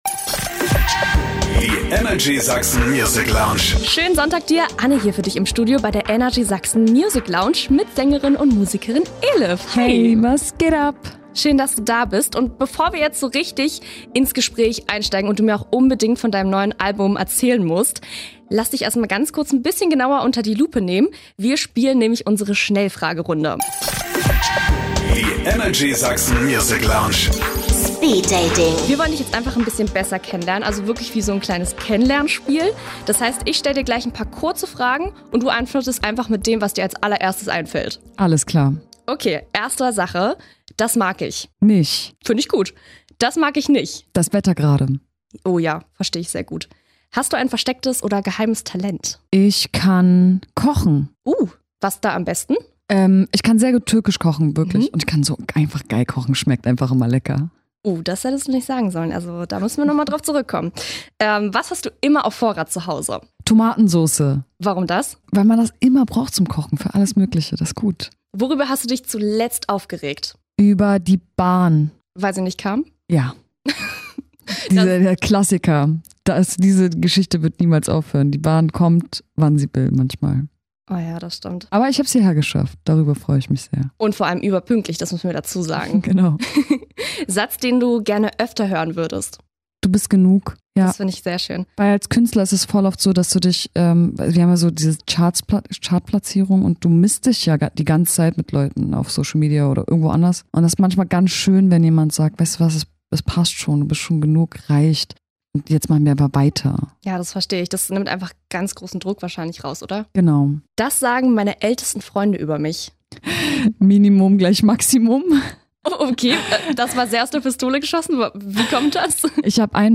Sängerin und Musikerin ELIF ist unser erster Gast in der ENERGY Sachsen Music Lounge!